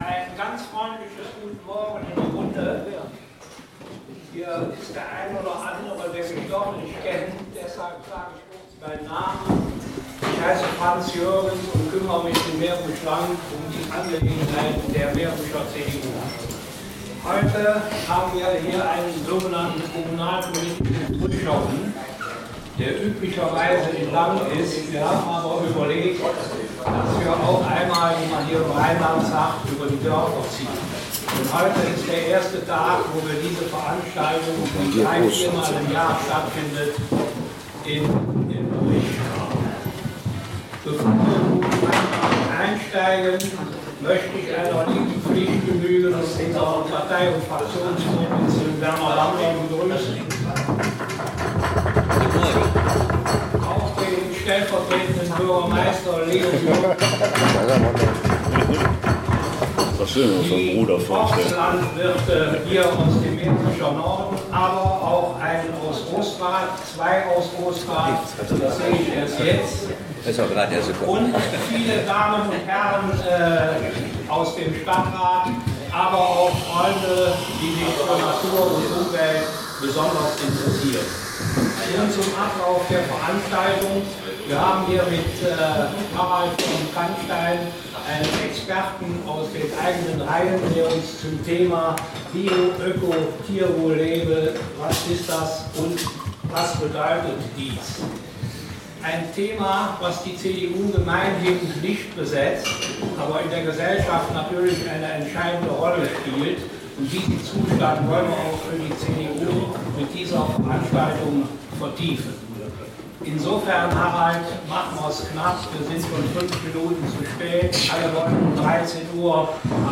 Im Anschluß folgte eine kontroverse Diskussion.